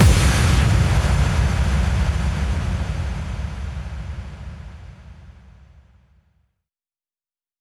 VTDS2 Song Kit 12 Female Nothing You Can Do FX Boom.wav